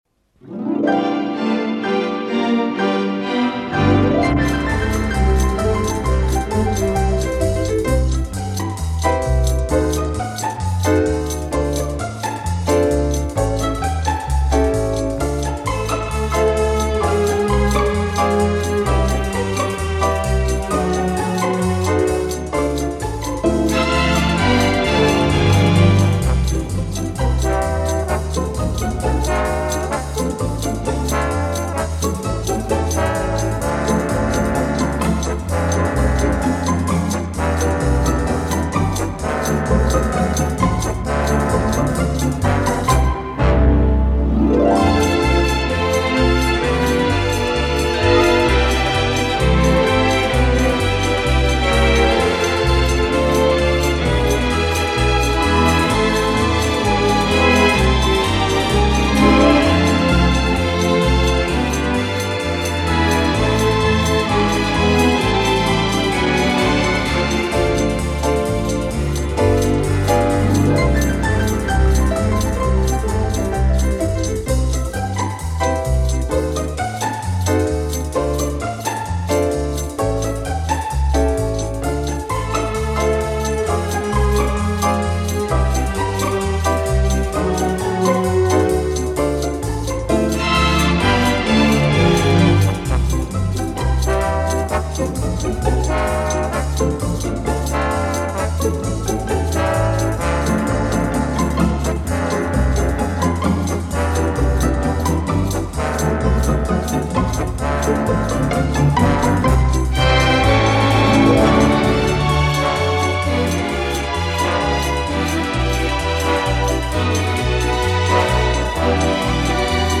Фокстрот.mp3